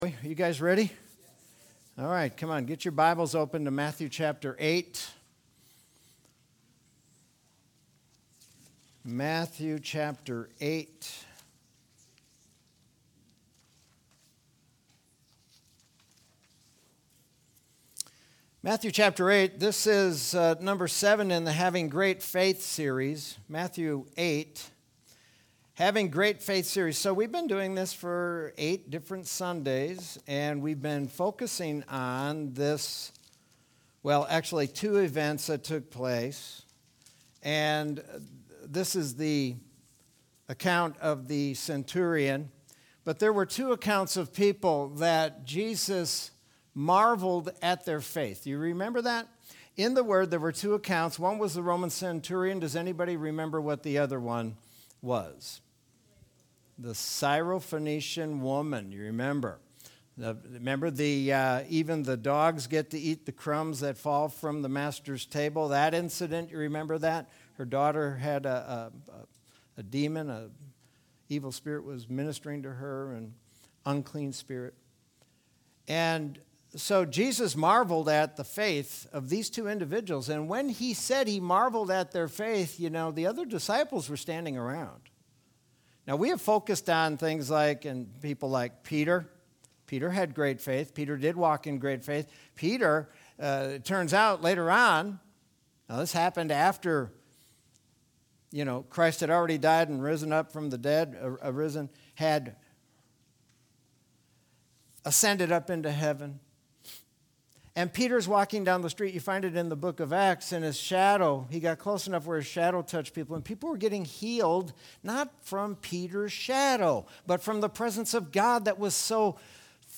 Sermon from Sunday, July 18th, 2021.